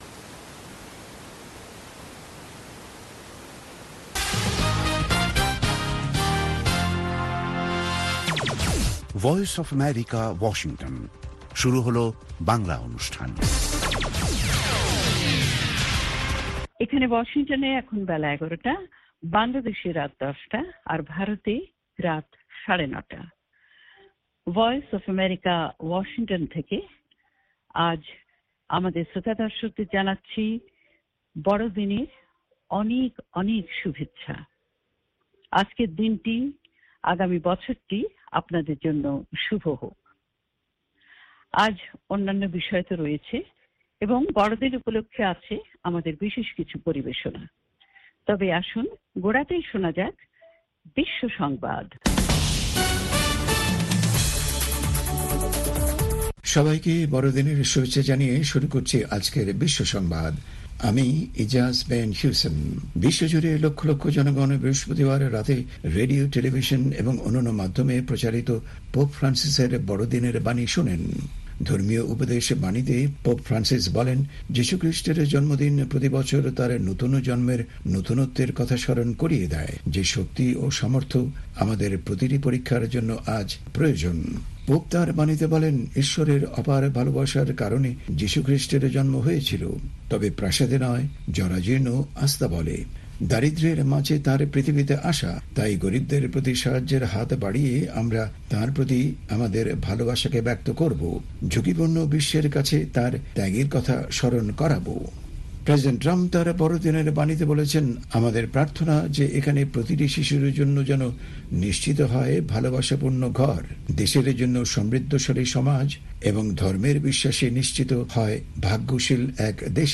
অনুষ্ঠানের শুরুতেই রয়েছে আন্তর্জাতিক খবরসহ আমাদের ঢাকা এবং কলকাতা সংবাদদাতাদের রিপোর্ট সম্বলিত ‘বিশ্ব সংবাদ’, এর পর রয়েছে ওয়ার্ল্ড উইন্ডোতে আন্তর্জাতিক প্রসংগ, বিজ্ঞান জগত, যুব সংবাদ, শ্রোতাদের চিঠি পত্রের জবাবের অনুষ্ঠান 'মিতালী' এবং আমাদের অনুষ্ঠানের শেষ পর্বে রয়েছে যথারীতি সংক্ষিপ্ত সংস্করণে বিশ্ব সংবাদ।